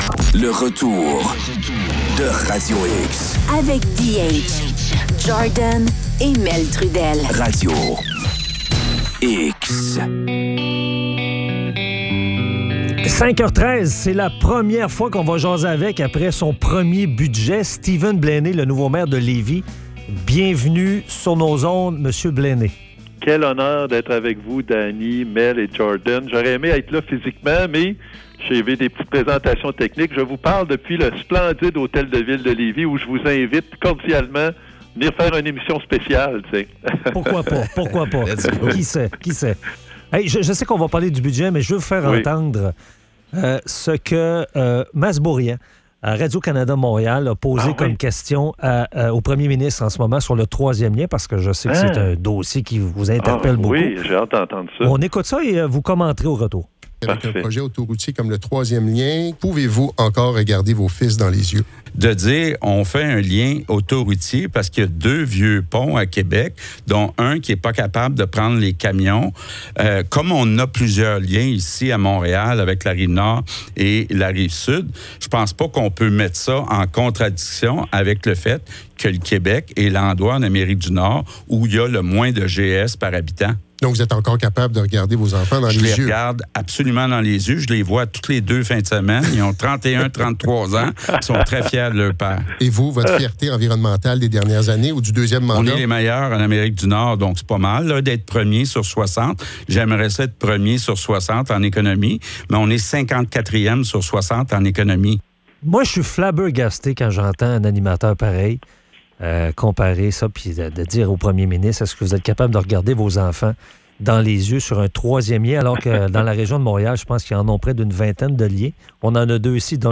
Entrevue avec Steven Blaney, maire de Lévis.